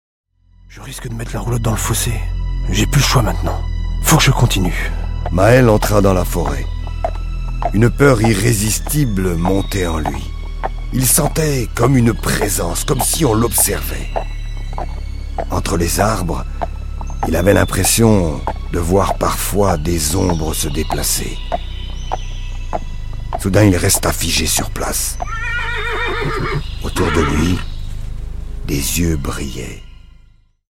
(conte)
Des chansons originales, des dialogues pris sur le vif, la voix envoutante d'un conteur, des musiques emplies d'émotion ... et, une fois n'est pas coutume, une fin aussi heureuse qu'inattendue.